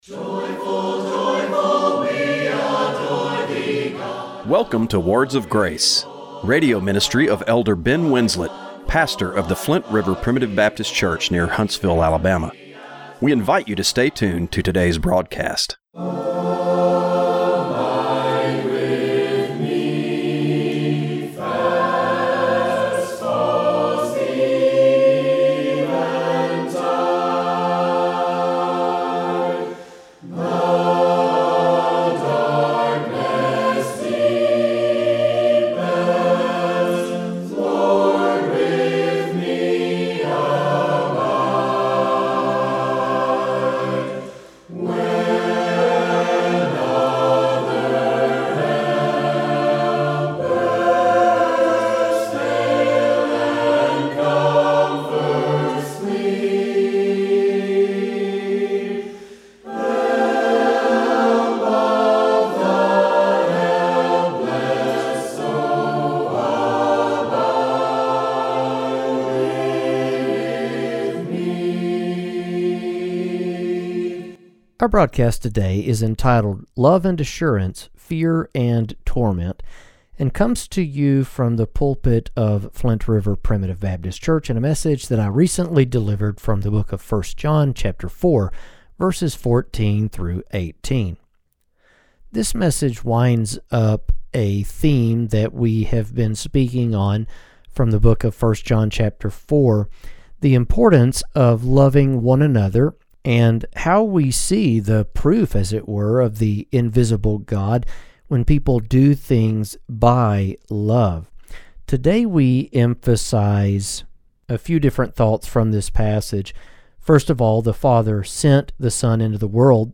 Radio broadcast for December 15, 2024.